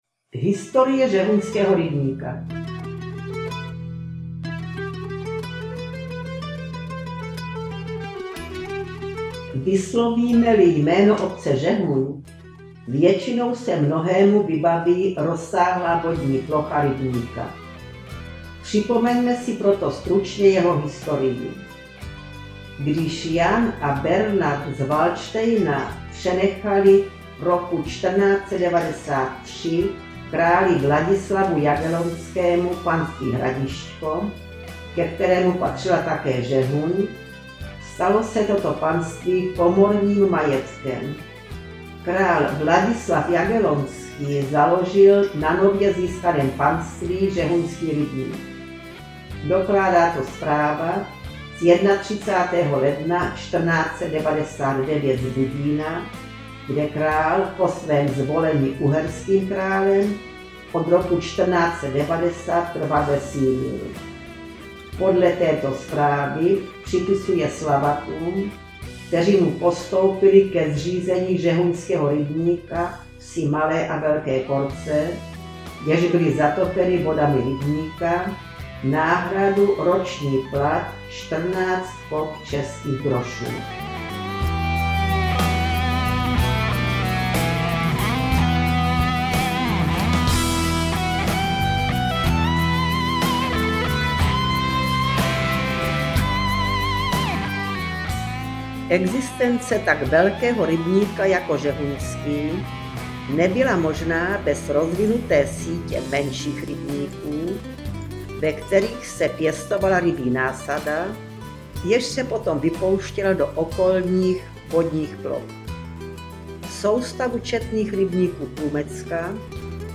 Zajímavý text s obrázky doplňuje mluvené slovo s hudbou.